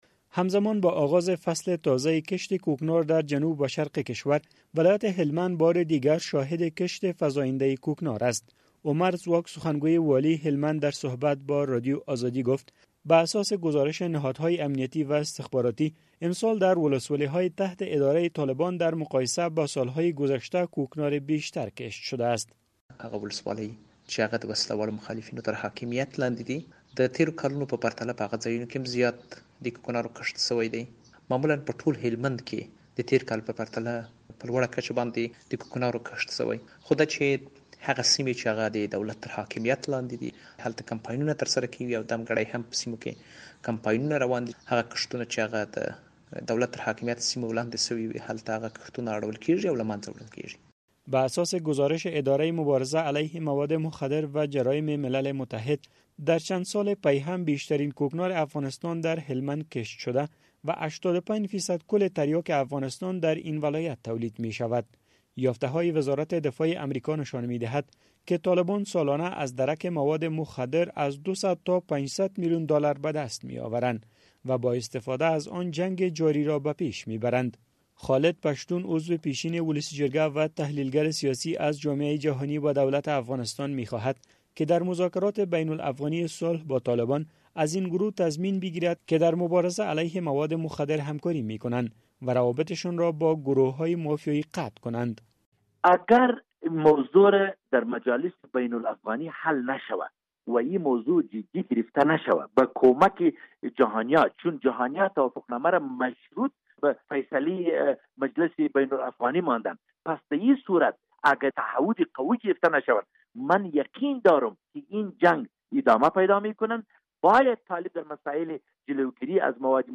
گزارش